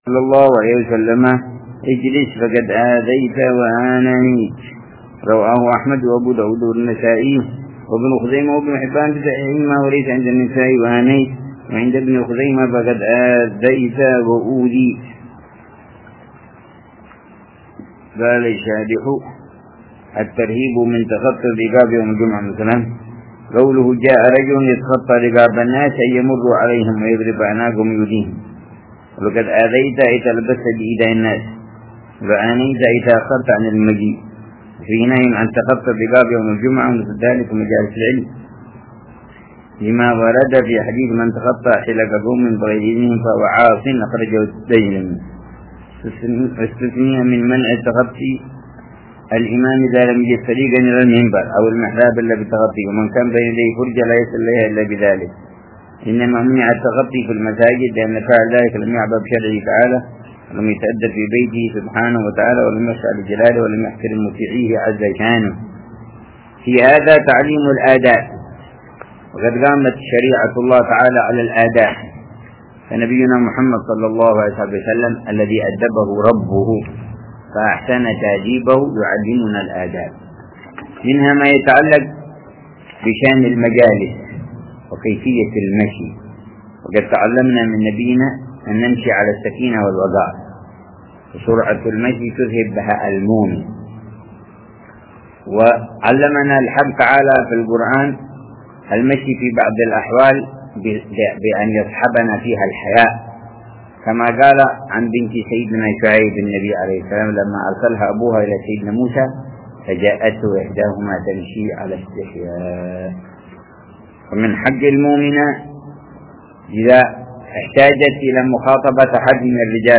شرح فتح القريب المجيب - الدرس الثامن عشر
الدرس الثامن عشر من شرح كتاب فتح القريب المجيب على تهذيب الترغيب والترهيب ، للسيد العلامة علوي بن عباس المالكي الحسني ، يلقيه الحبيب عمر بن حف